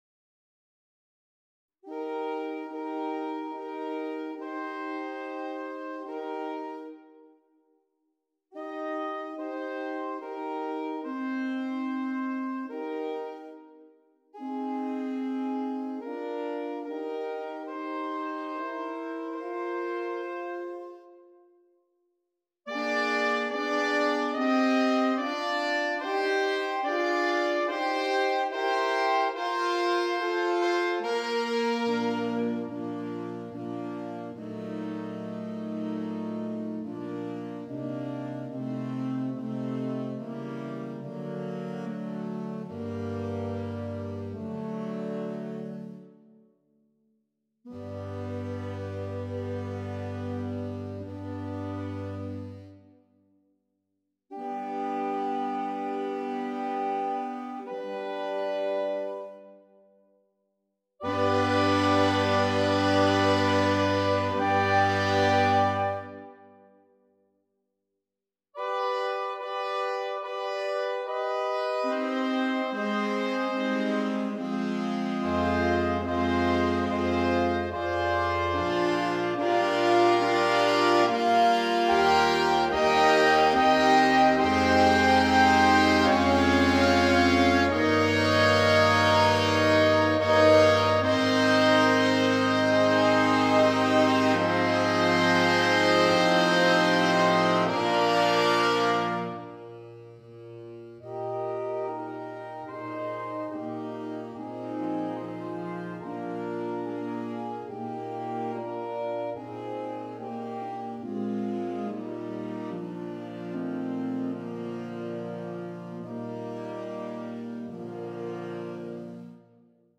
Voicing: Saxophone Nonet (SAAAAATTB)